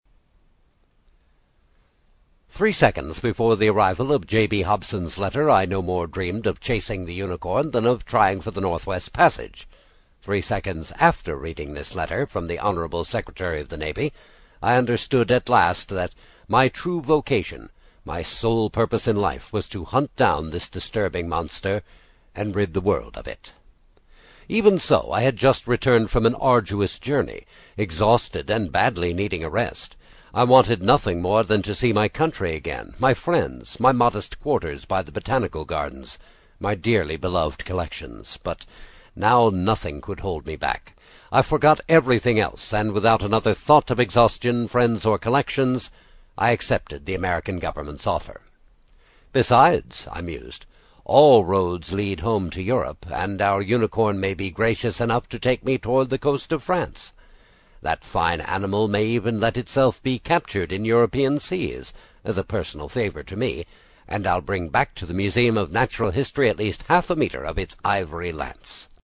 英语听书《海底两万里》第23期 第3章 随您先生的便(1) 听力文件下载—在线英语听力室
在线英语听力室英语听书《海底两万里》第23期 第3章 随您先生的便(1)的听力文件下载,《海底两万里》中英双语有声读物附MP3下载